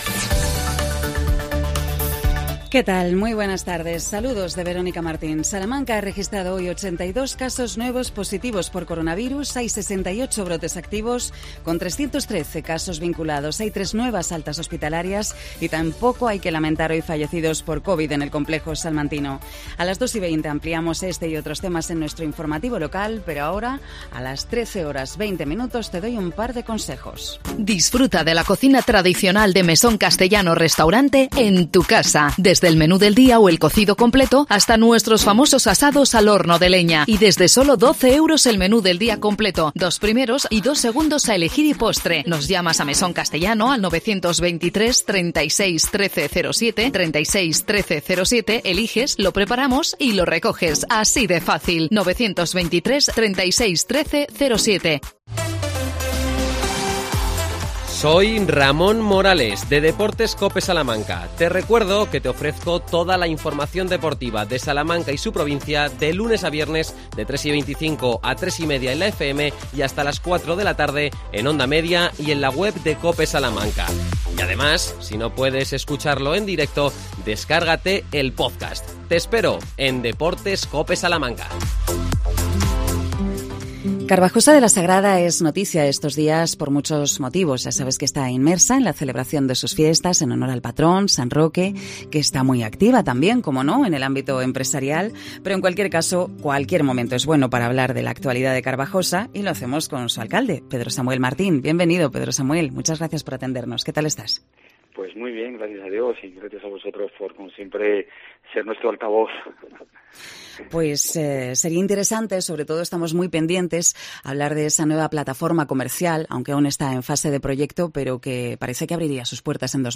AUDIO: El alcalde de Carbajosa de la Sagrada nos habla de las fiestas en era COVID y del nuevo parque comercial.